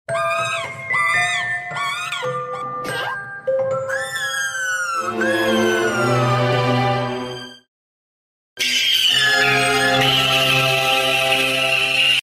YIPPEE sound effects free download